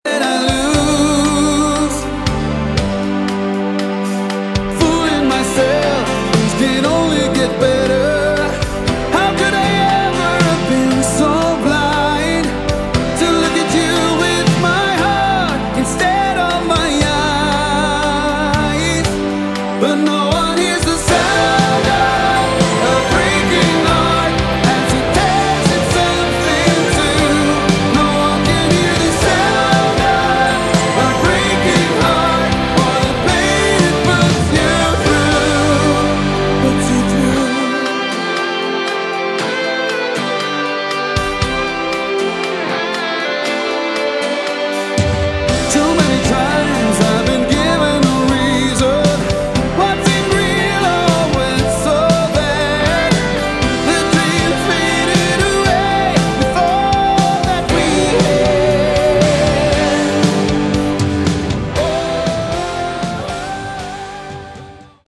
Category: AOR / Melodic Rock
lead, backing vocals
lead guitar
keyboards, piano
bass guitar